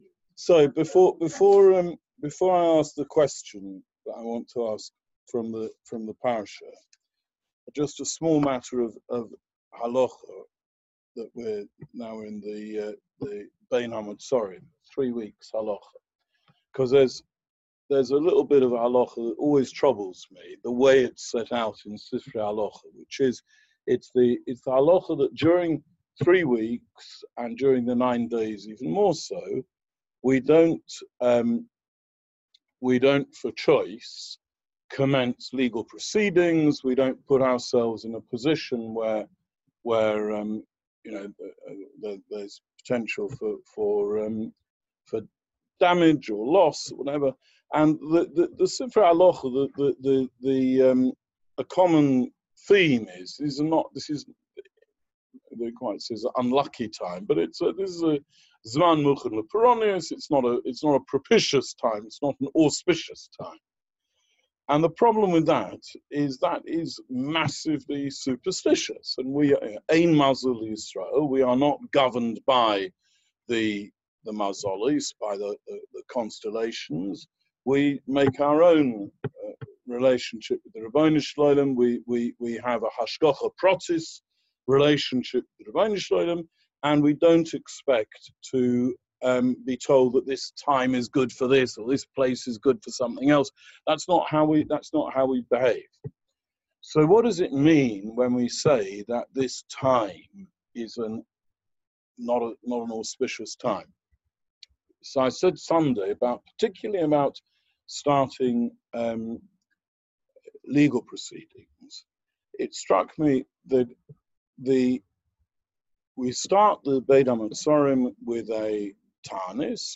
June 2019 – Community Shiur on Parashas Behaalosecho – Dangers of Religious Institutional Corruption https